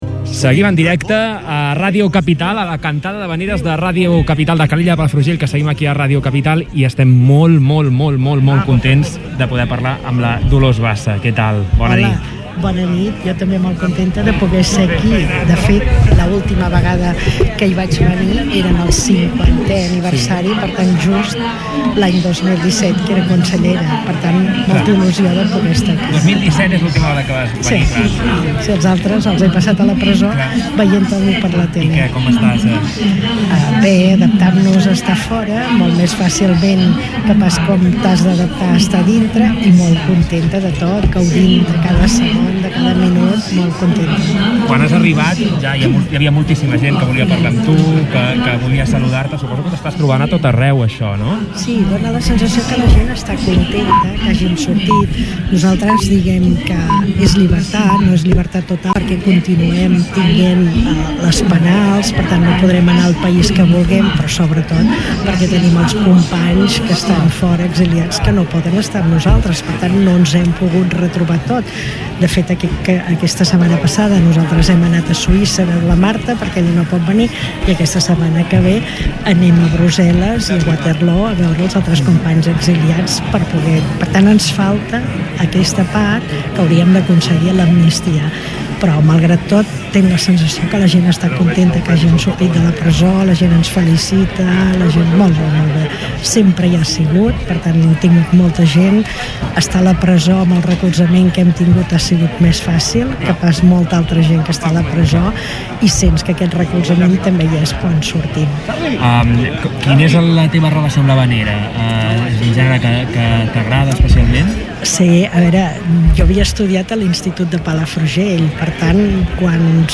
A Ràdio Capital vam tenir el privilegi de ser-hi a primera línia: amb un estudi mòbil vam fer el seguiment de tota la cantada, entrevistes a tothom qui la va fer possible, i fins i tot, vam passar una estona amb Dolors Bassa.
Vam ser molt feliços de tornar a tenir a la taula de l’estudi una de les dones de la família de Ràdio Capital.